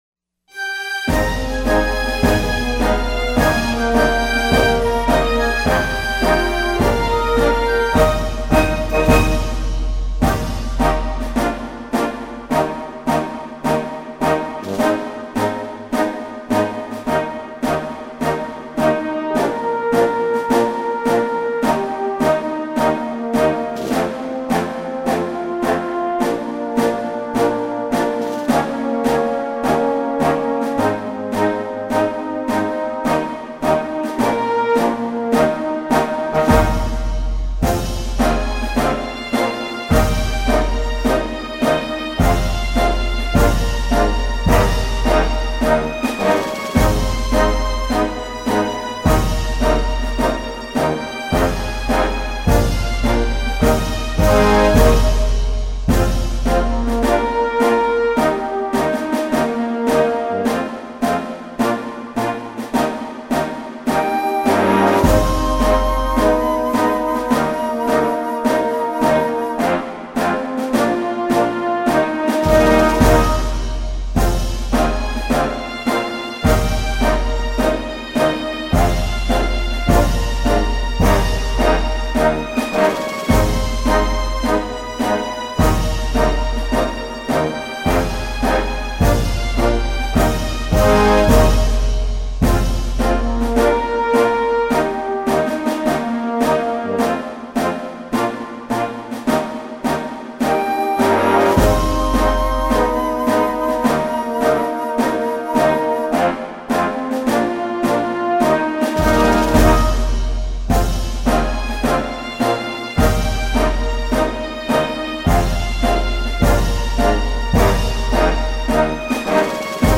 - Instrumental -